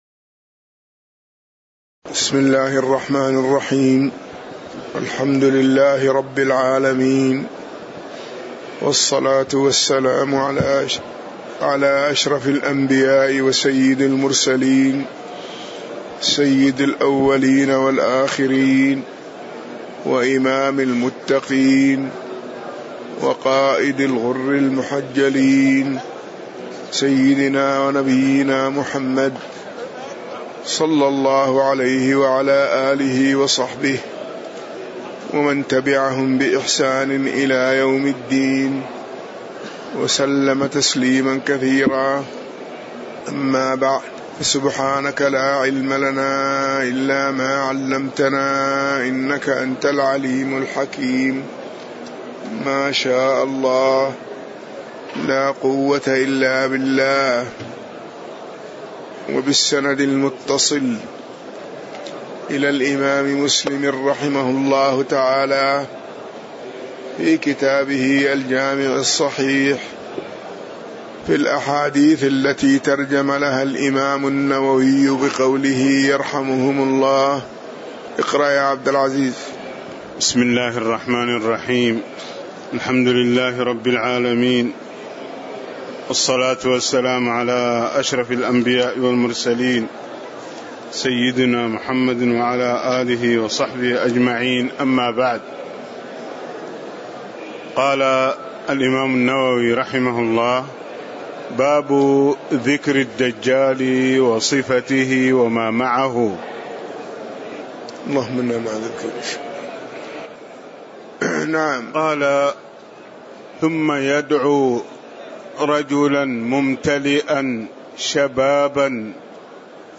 تاريخ النشر ٦ شعبان ١٤٣٨ هـ المكان: المسجد النبوي الشيخ